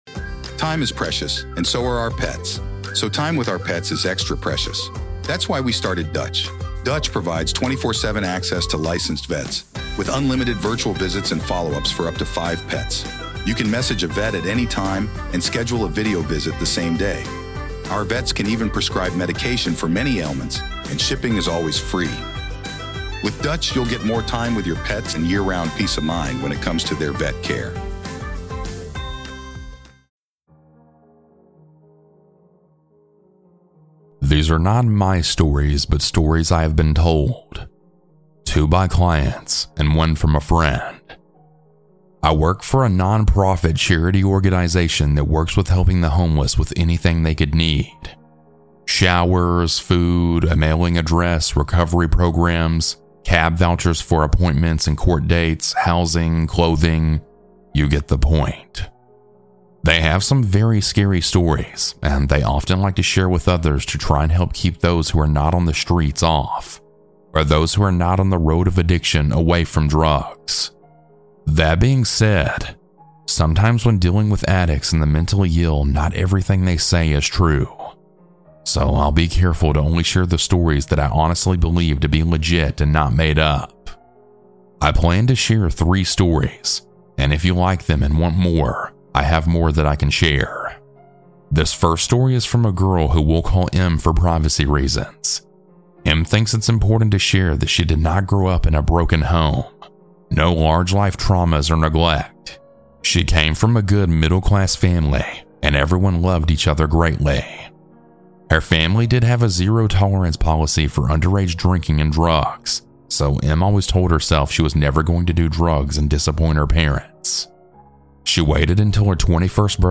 Huge Thanks to these talented folks for their creepy music!